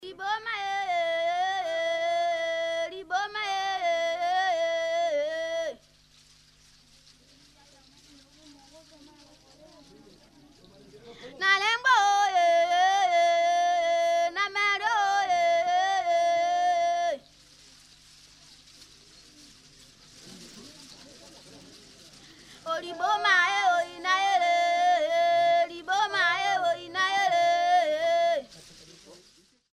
Percussion pot
The percussion pot is played by men and women who either squat beside the vessel or stand with the vessel held under their arm. The sound is produced by striking the opening of the pot with a fan-shaped beater made from banana fibres. The percussion pot is played without fluids added to change the pitch.